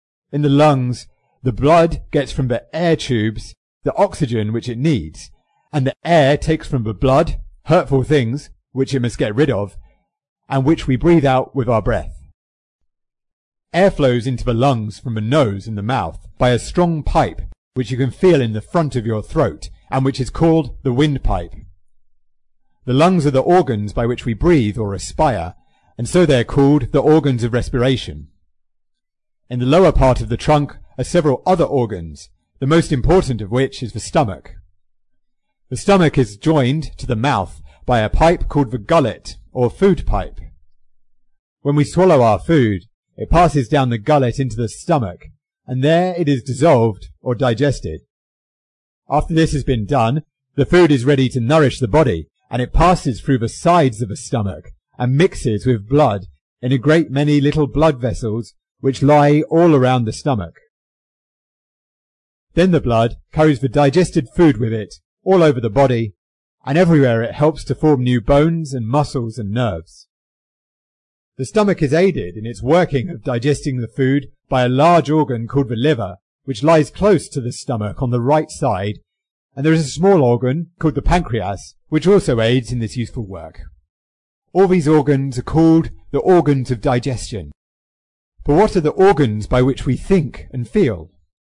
在线英语听力室英国学生科学读本 第97期:人体(4)的听力文件下载,《英国学生科学读本》讲述大自然中的动物、植物等广博的科学知识，犹如一部万物简史。在线英语听力室提供配套英文朗读与双语字幕，帮助读者全面提升英语阅读水平。